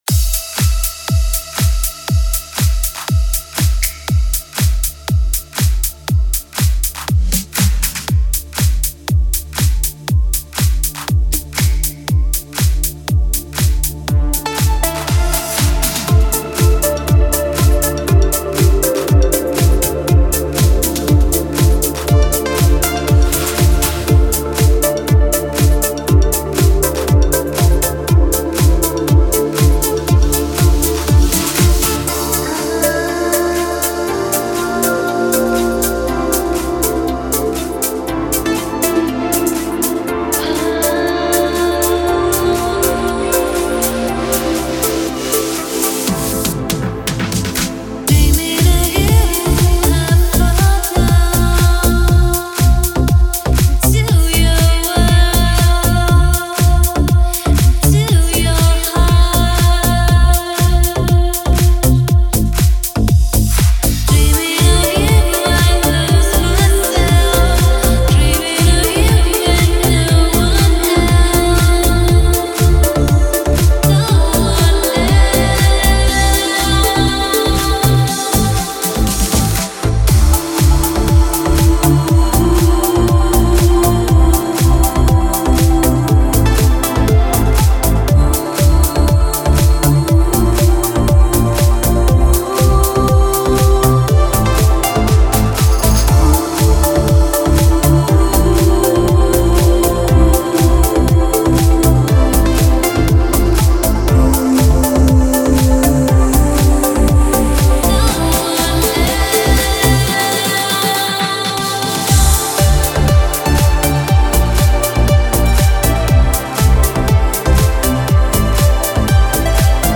это завораживающая композиция в жанре поп с элементами инди